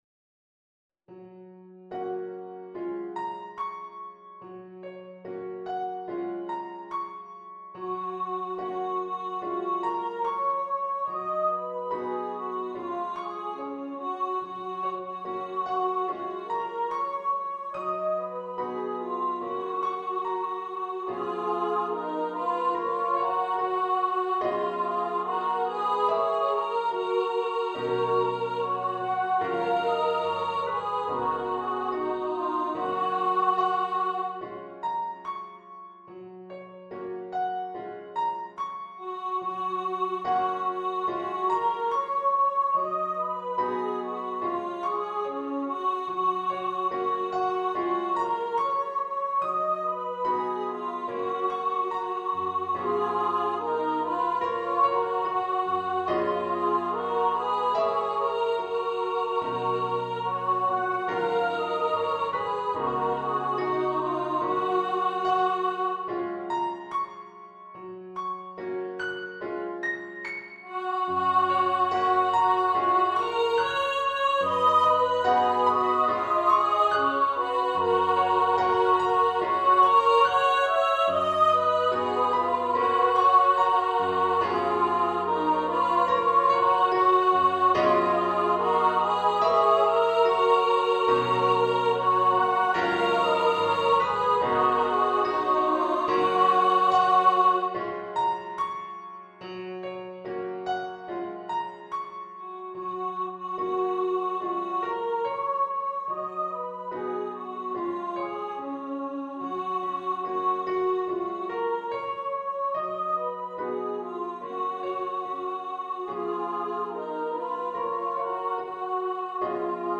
for upper voice choir and piano
A gentle, soothing
Choir - 2 part upper voices